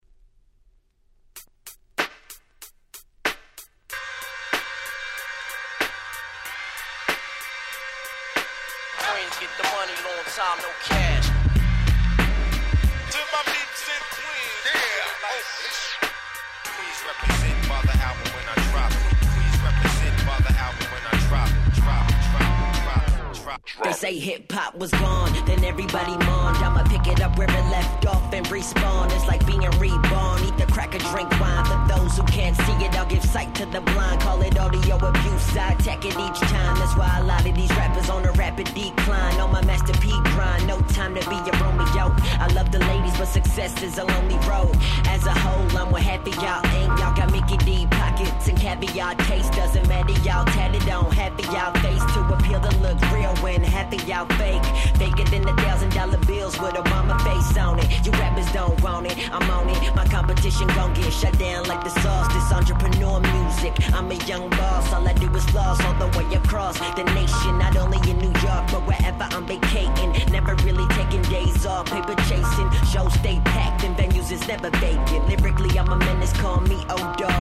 09' Nice Hip Hop !!